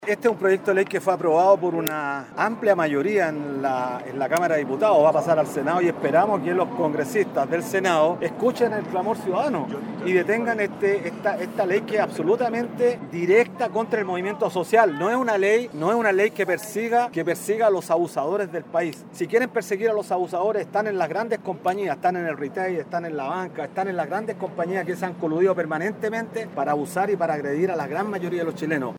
Hasta el frontis de la sede del Congreso en Santiago llegaron durante este viernes algunos de los dirigentes de la denominada Mesa de Unidad Social, quienes manifestaron su descontento con la agenda de seguridad impulsada por el Gobierno en el Congreso.